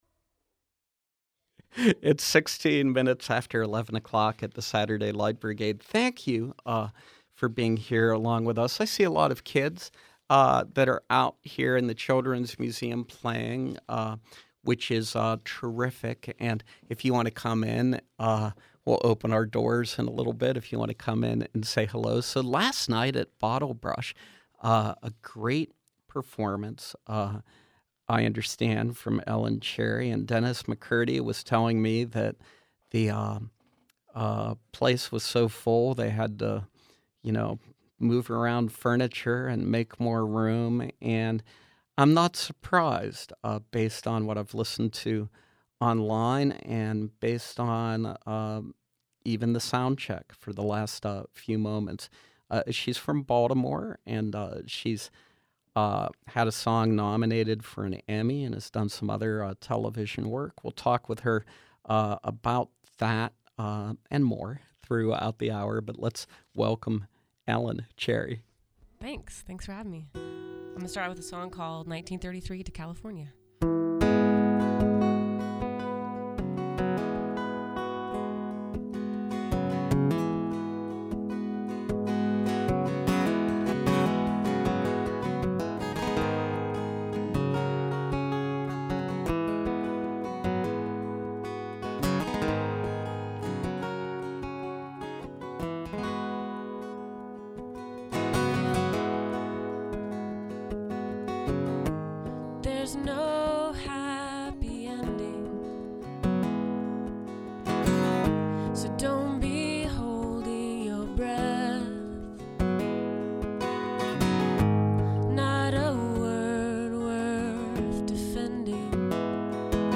performing folk music that is rich in narrative and skill